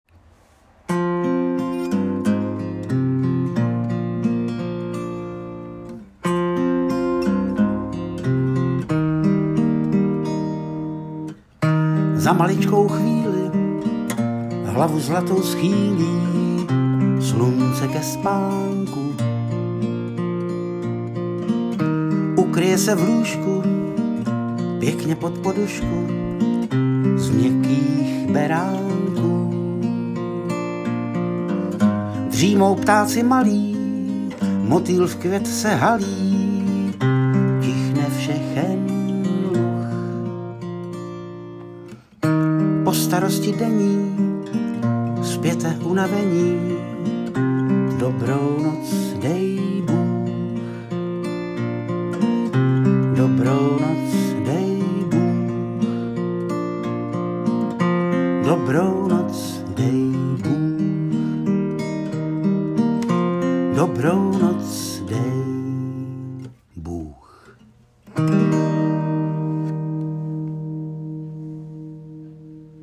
V neděli 28. srpna proběhlo slavnostní otevření nového městského úřadu a upravené severní části náměstí K. V. Raise v Lázních Bělohradě.
V průběhu odpoledne pak přítomným zahráli skupina Dolphin Jazz a písničkář Mirek Paleček, který přítomné potěšil zhudebněním veršů Karla Václava Raise Cestička k domovu a Večer.